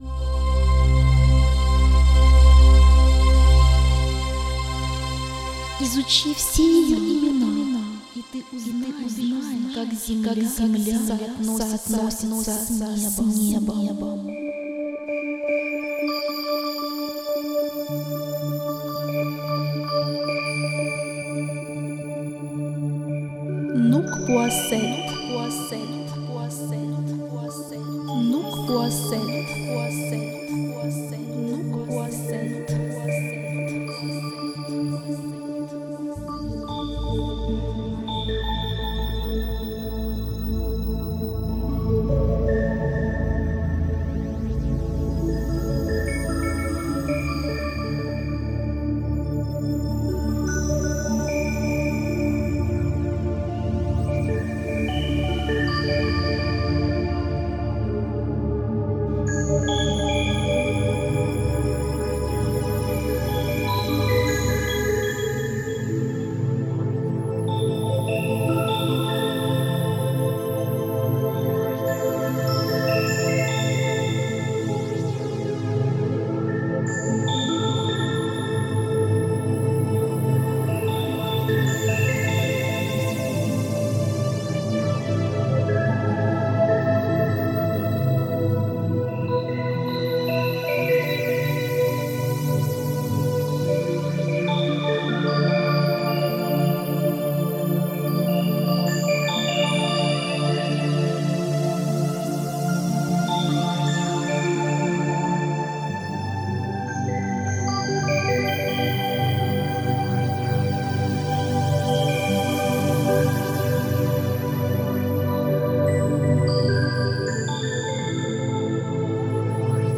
Медитативная музыка Духовная музыка Мистическая музыка